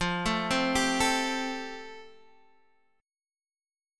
Fadd9 chord